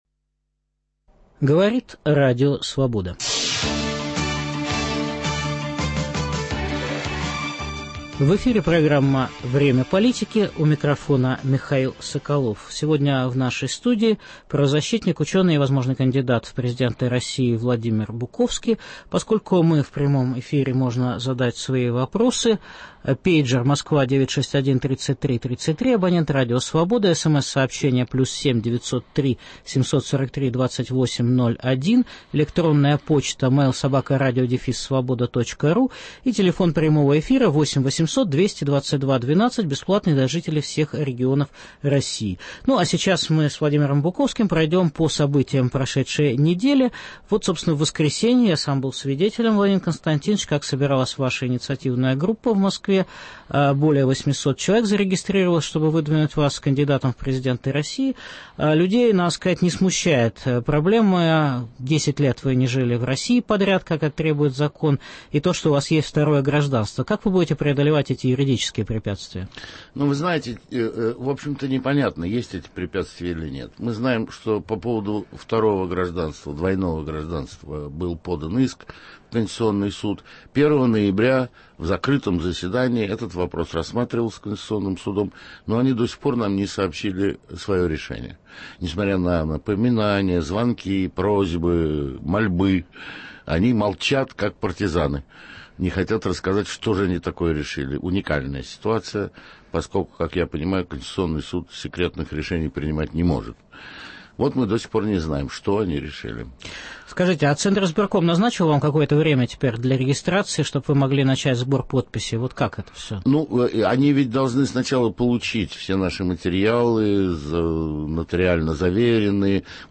В прямом эфире выступит правозащитник и ученый, потенциальный участник президентских выборов Владимир Буковский.